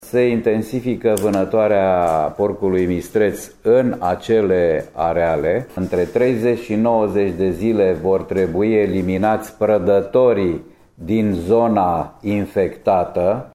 Directorul executiv al DSVSA Braşov, Dorin Enache: